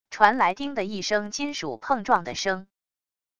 传来叮的一声金属碰撞的声wav音频